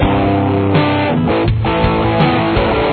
Main Theme